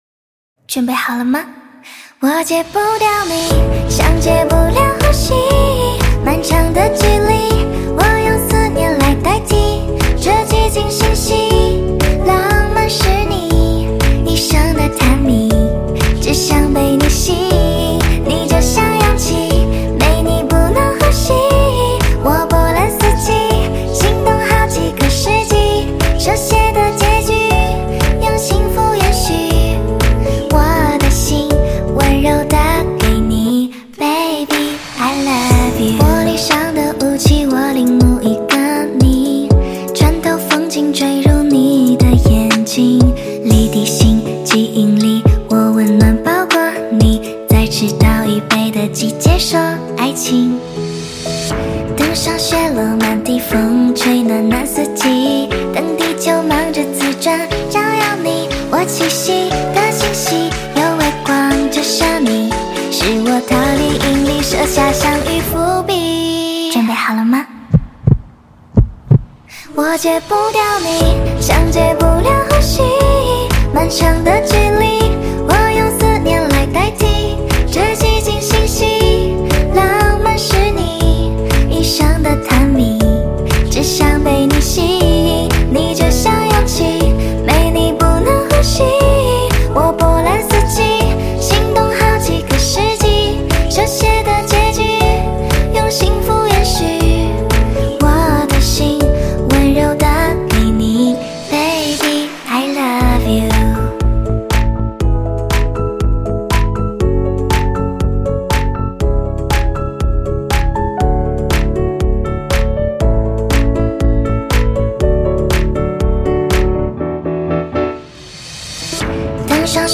Ps：在线试听为压缩音质节选，体验无损音质请下载完整版
翻唱2022